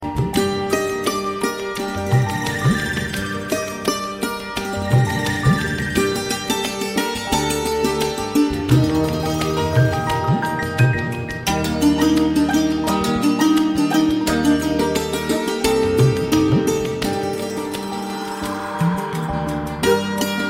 Santoor.mp3